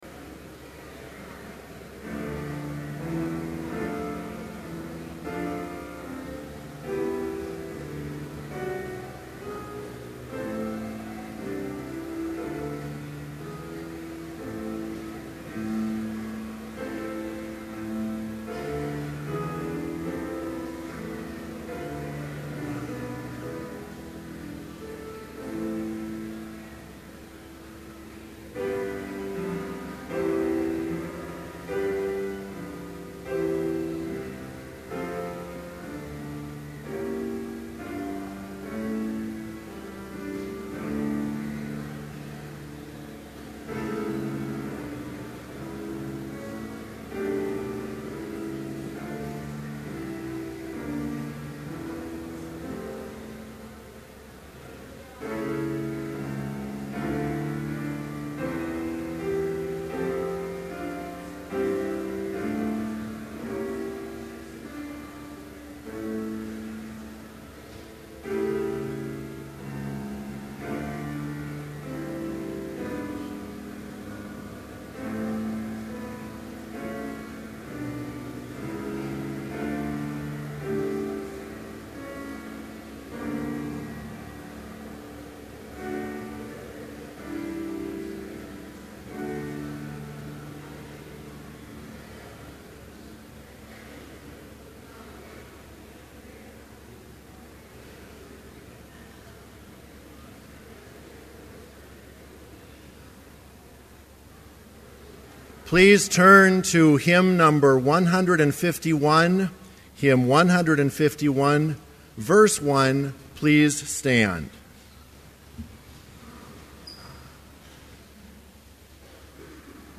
Complete service audio for Chapel - February 2, 2012